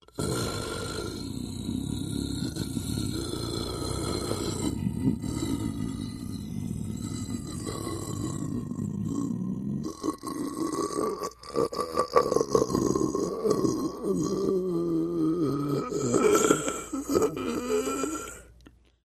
Звук зомби: кряхтит и стонет (долго)
Библиотека Звуков - Звуки и звуковые эффекты - Зомби, мертвецы